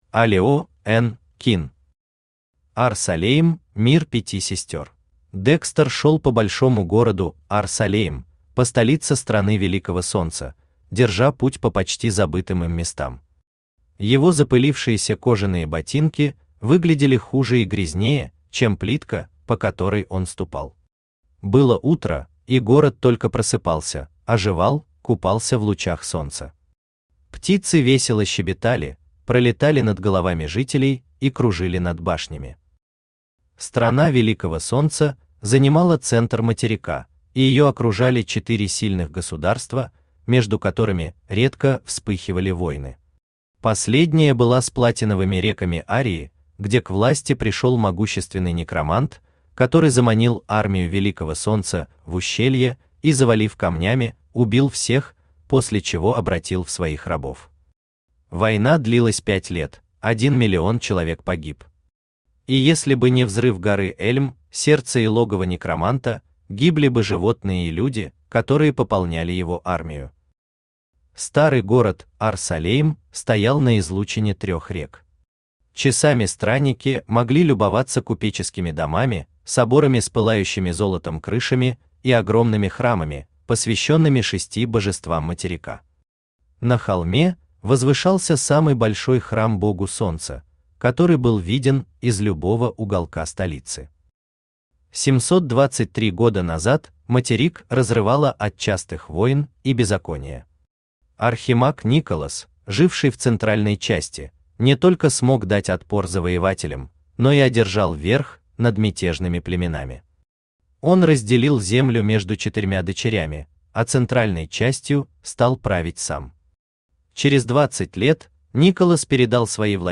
Аудиокнига Ар-Салейм Мир Пяти Сестер | Библиотека аудиокниг
Aудиокнига Ар-Салейм Мир Пяти Сестер Автор Олео Н.Кин Читает аудиокнигу Авточтец ЛитРес.